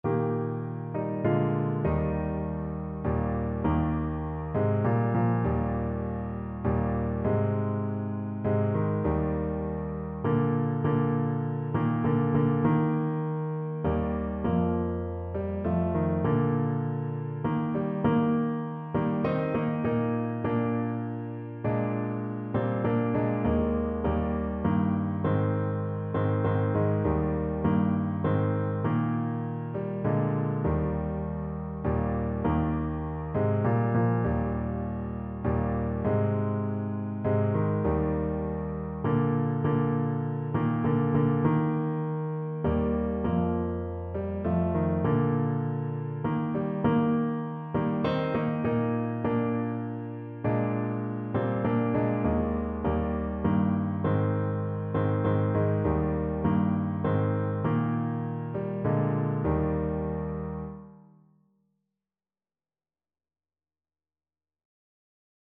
Christian
3/4 (View more 3/4 Music)
Classical (View more Classical Trumpet Music)